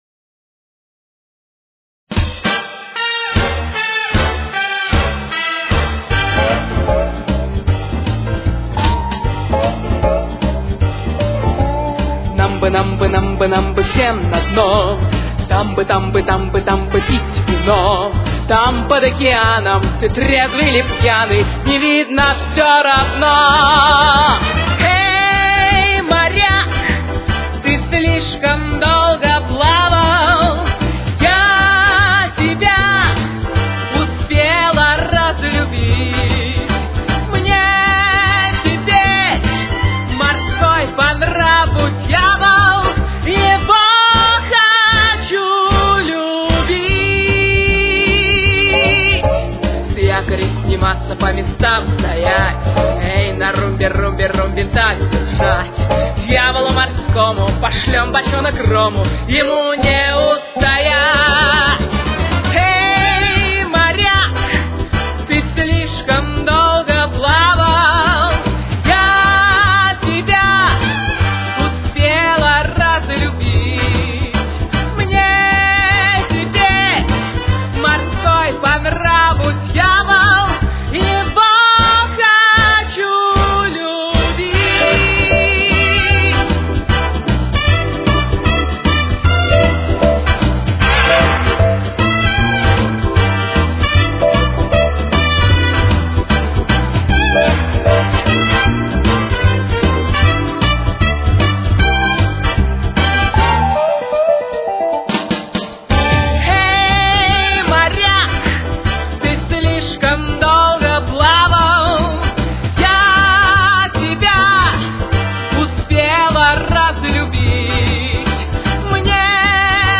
с очень низким качеством (16 – 32 кБит/с)
Соль минор. Темп: 157.